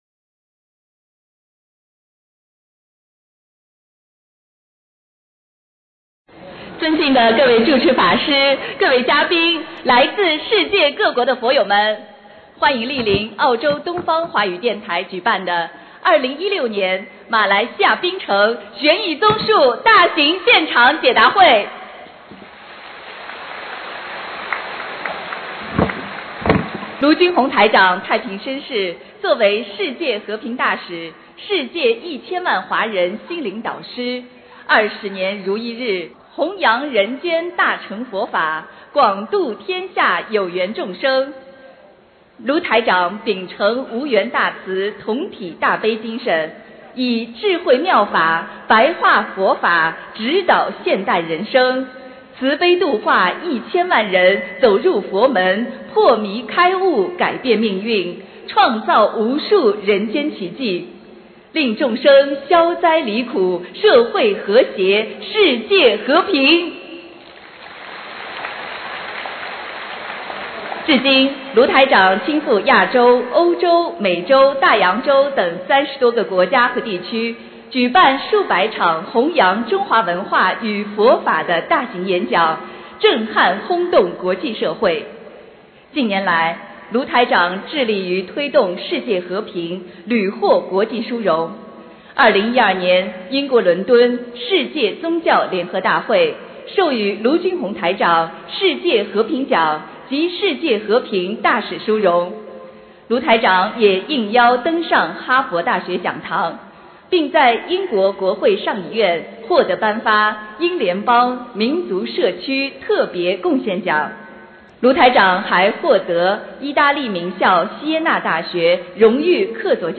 2016年8月13日马来西亚槟城解答会开示（视音文图） - 2016年 - 心如菩提 - Powered by Discuz!